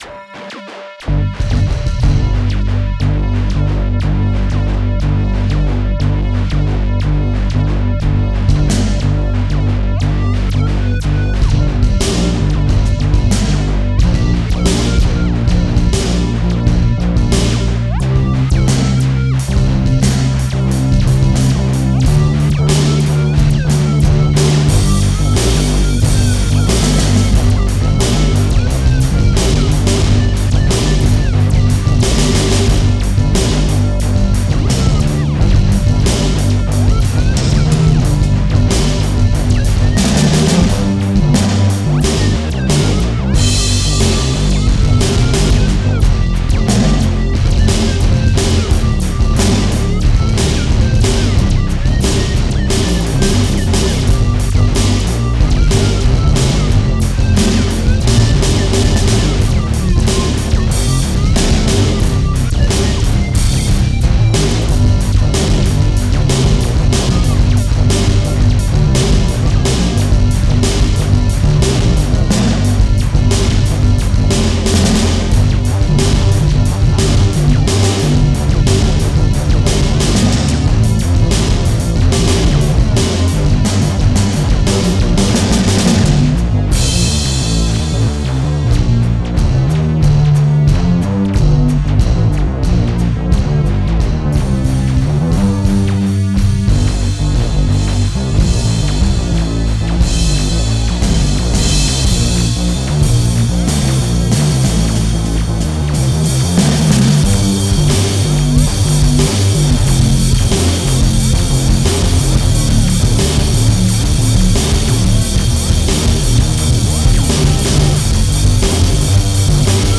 Various Genres (2018)